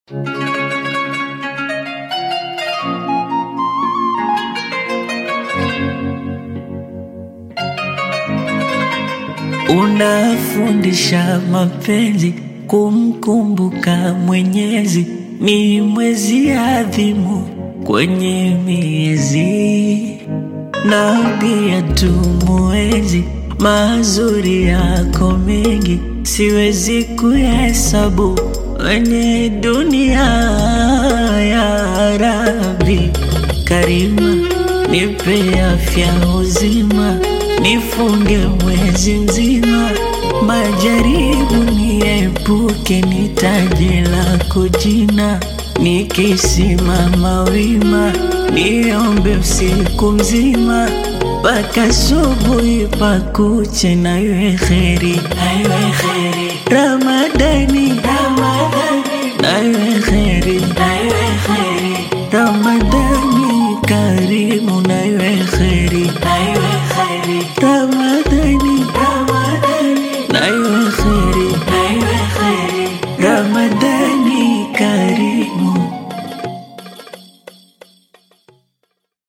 Qaswida You may also like